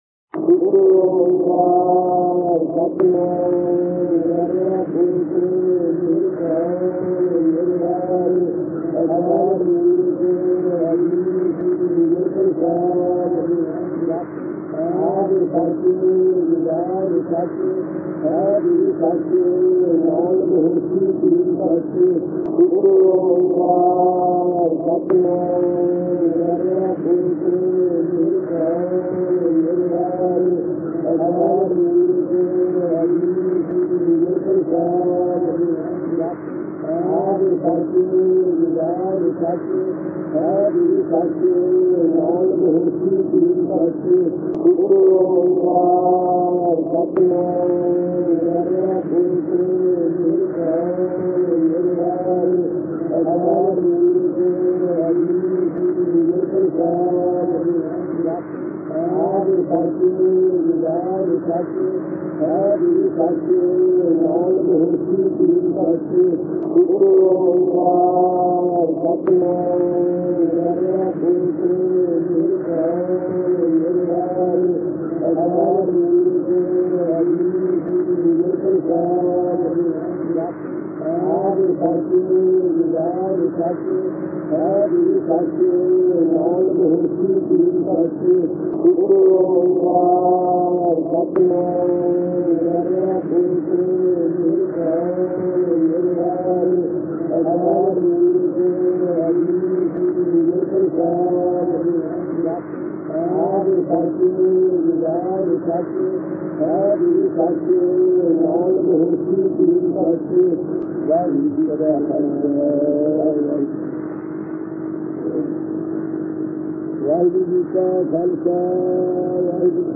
Sant Giani Gurbachan Singh Ji – Katha | Damdami Taksal